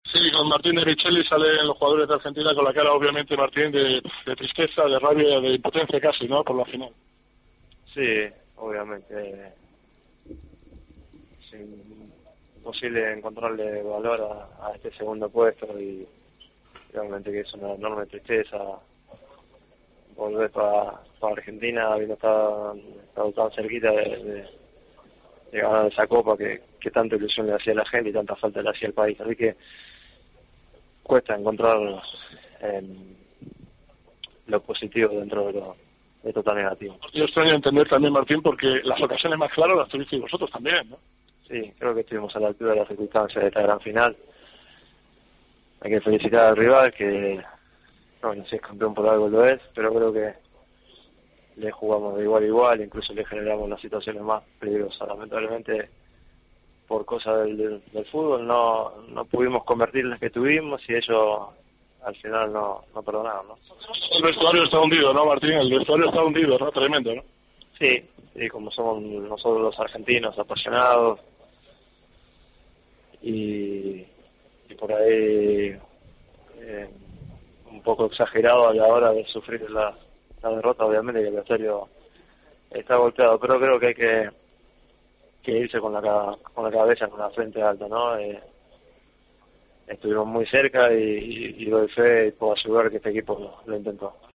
"Es imposible encontrarle valor al segundo puesto", ha dicho, destrozado, el central del Manchester City en Tiempo de Juego.